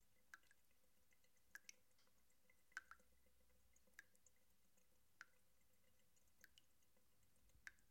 描述：水滴从旧的水龙头foley
Tag: 滴水 水龙头